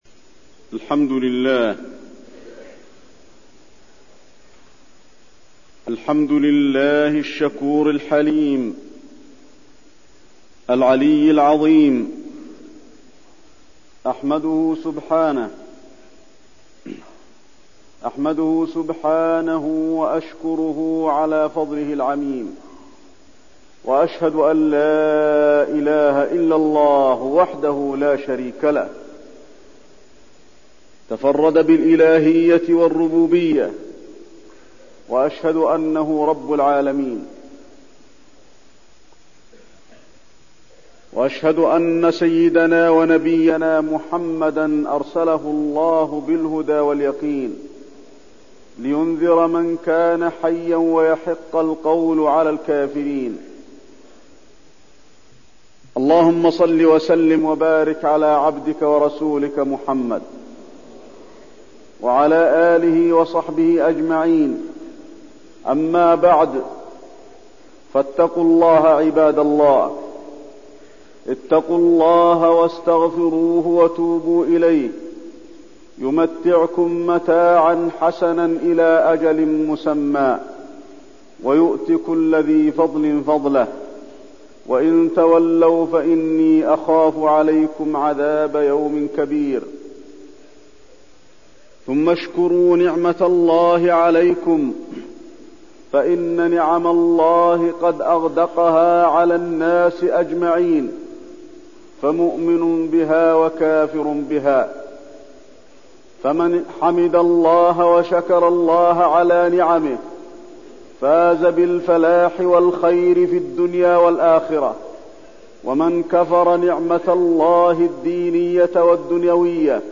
تاريخ النشر ٣ جمادى الآخرة ١٤٠٥ المكان: المسجد النبوي الشيخ: فضيلة الشيخ د. علي بن عبدالرحمن الحذيفي فضيلة الشيخ د. علي بن عبدالرحمن الحذيفي شكر النعمة